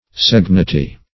segnity - definition of segnity - synonyms, pronunciation, spelling from Free Dictionary
Search Result for " segnity" : The Collaborative International Dictionary of English v.0.48: Segnitude \Seg"ni*tude\, Segnity \Seg"ni*ty\, n. [L. segnitas, fr. segnis slow, sluggish.]